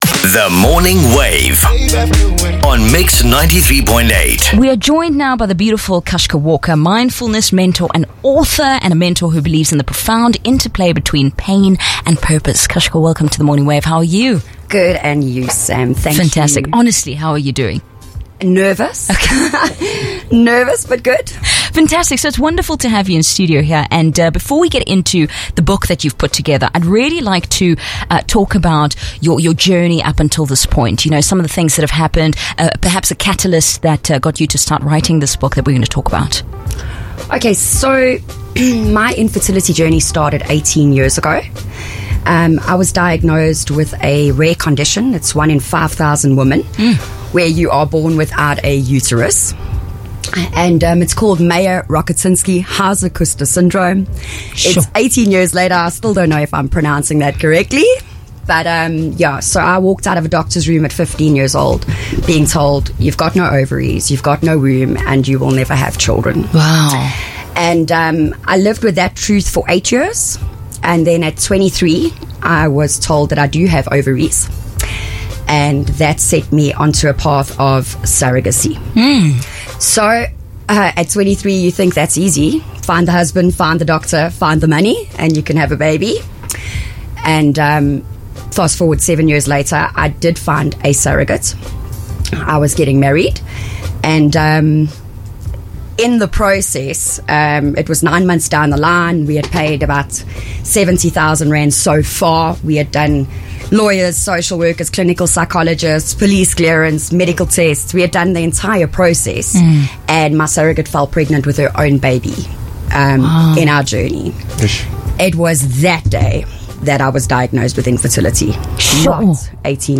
Mix 93.8 FM Interview